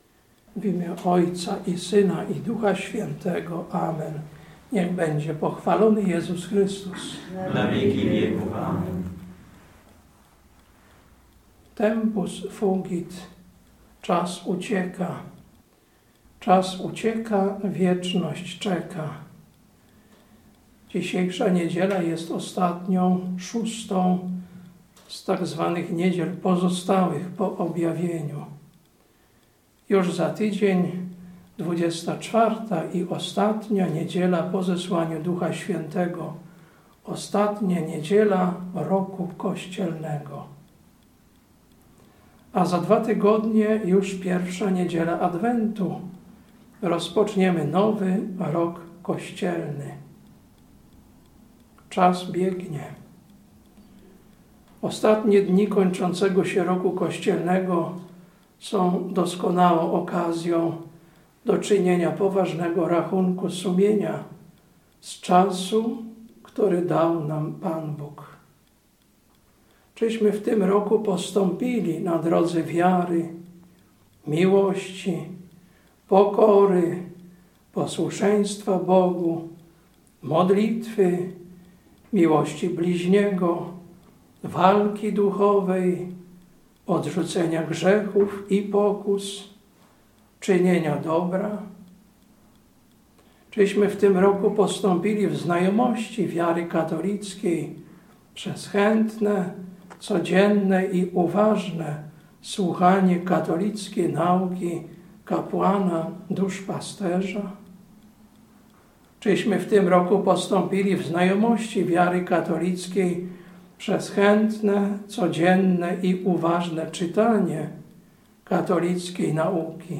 Kazanie na 6 Niedzielę Pozostałą po Objawieniu, 17.11.2024